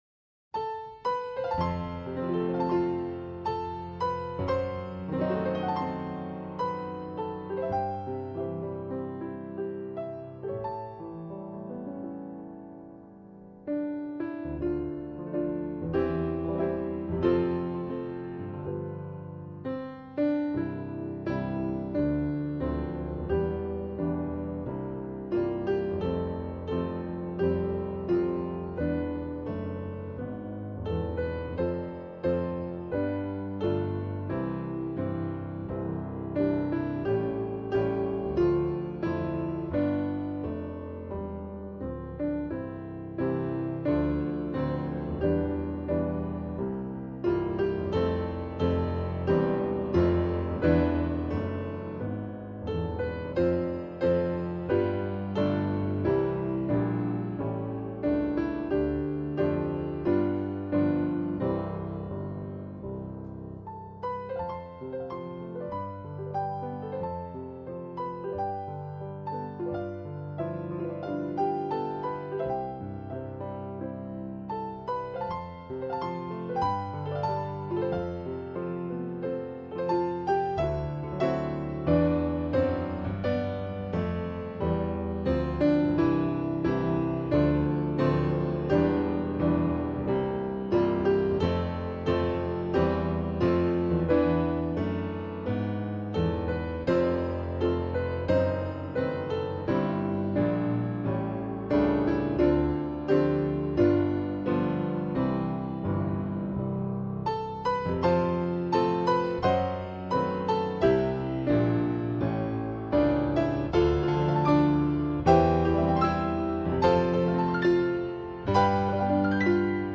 เพลงพระราชนิพนธ์, เปียโน